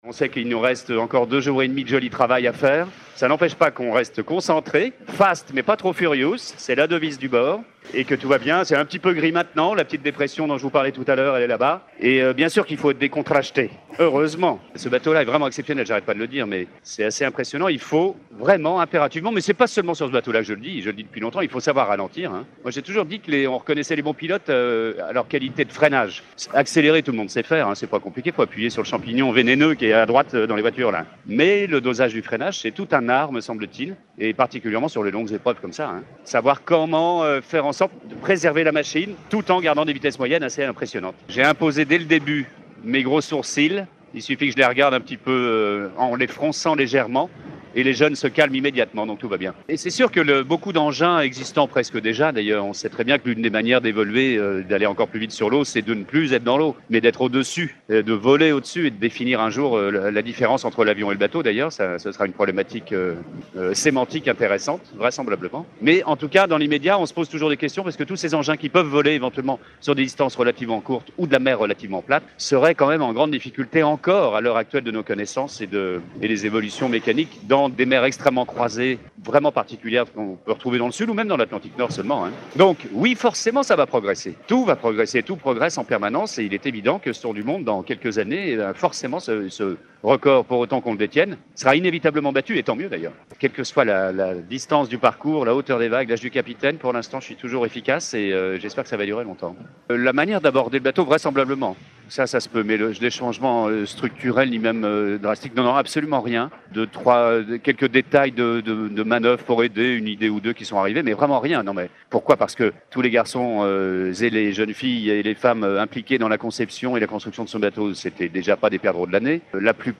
And he added, even before going over the finish line: “Technically, everything’s going to improve, and it’s obvious that this record will probably be broken in a few years’ time…”(10)(10)Radio session, January the 4th , with Loïck Peyron.